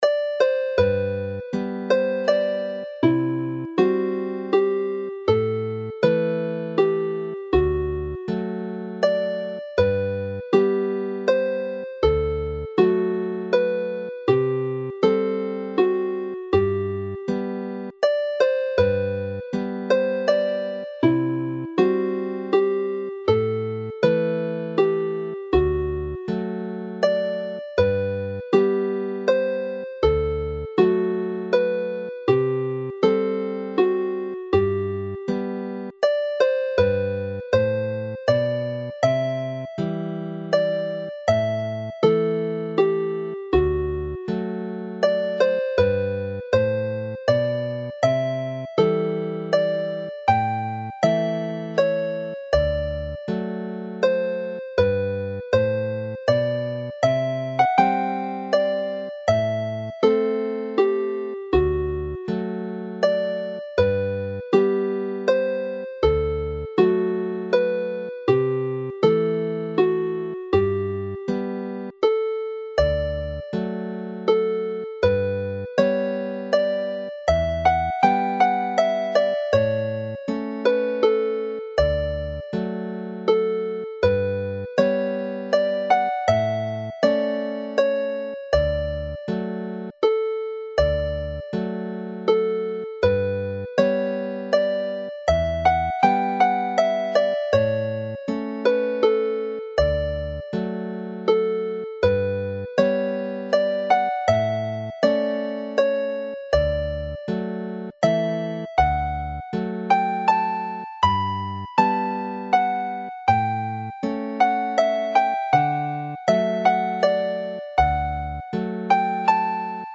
Play the set slowly